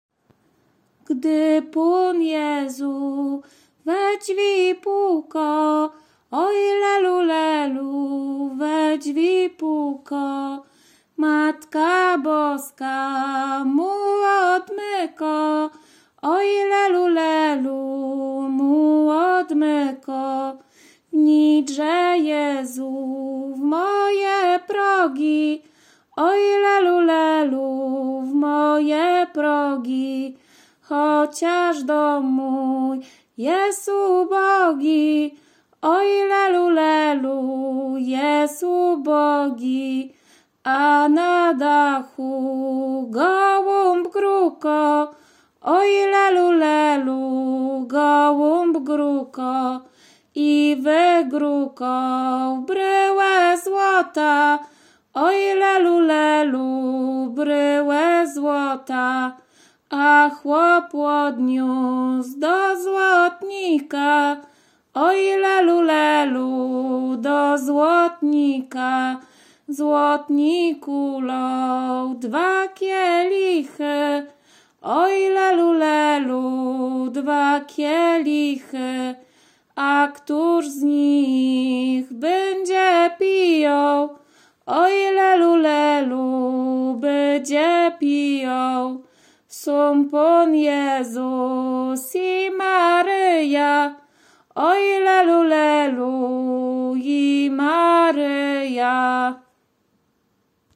Łódzkie, powiat sieradzki, gmina Brzeźnio, wieś Kliczków Mały
Kolęda